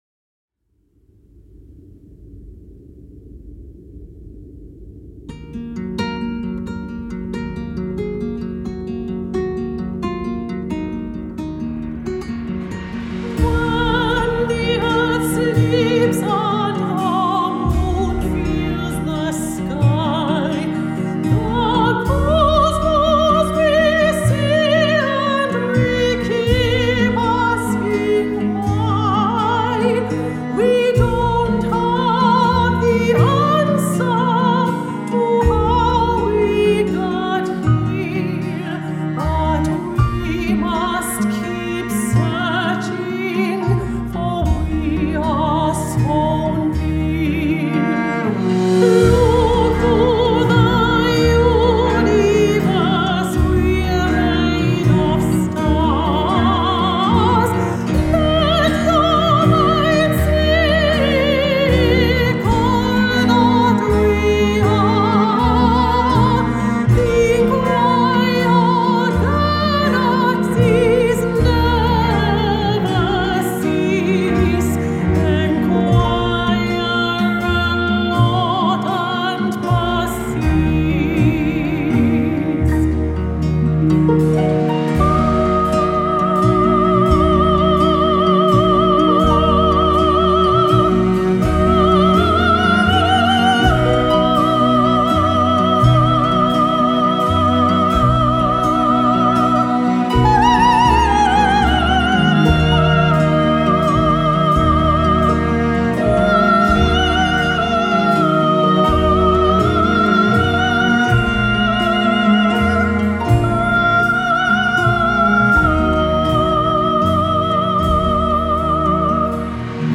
With over twenty minutes of classical/folk/ crossover tracks
crystal clear spinto soprano voice
modern acoustic sounds
Airtight Studios in Manchester